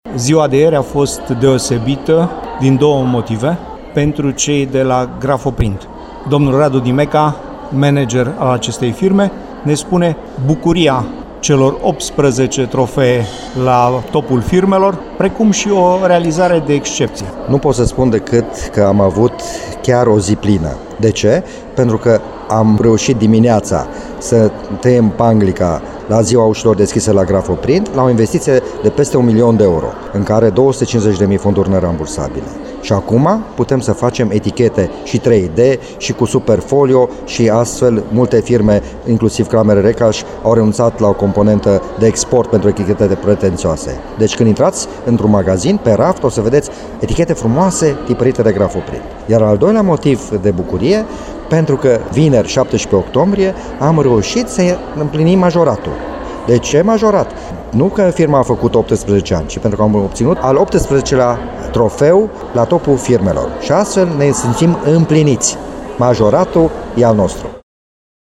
Interviuri